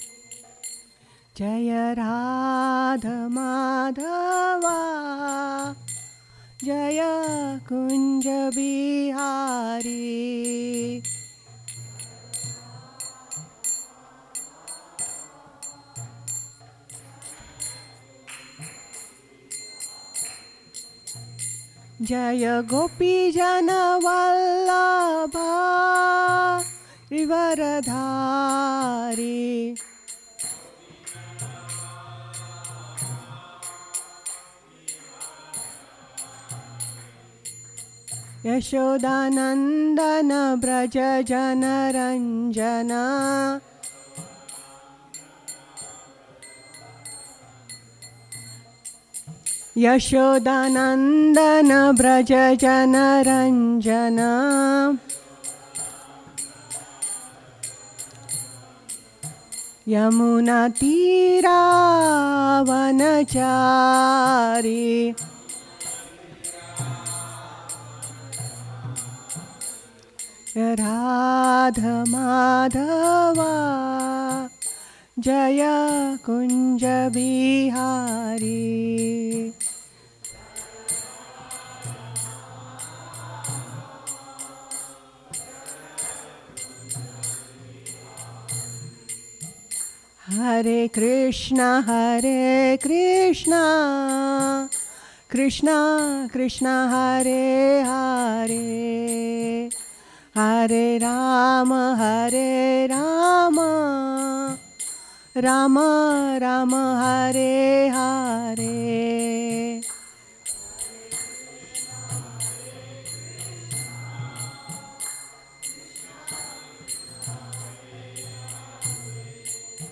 Lectures and kirtanas (devotional music) from the Hare Krishna temple in Alachua, Florida.